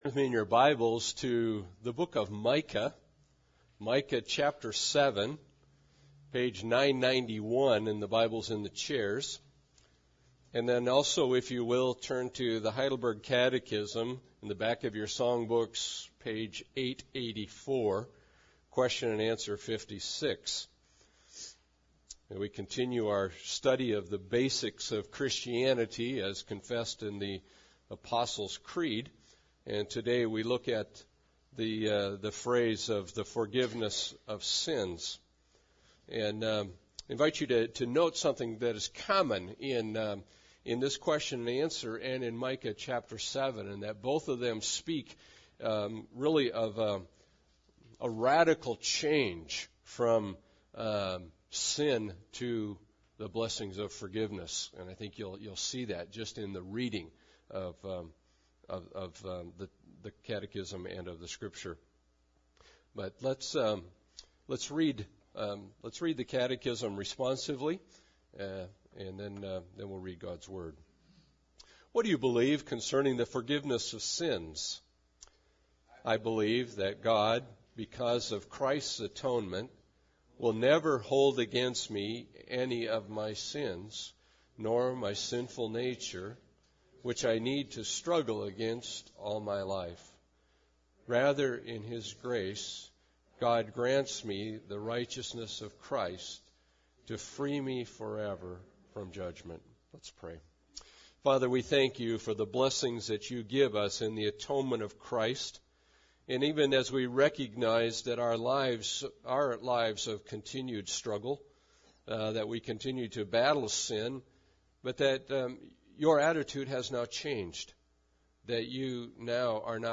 Passage: Micah 7 Service Type: Sunday Service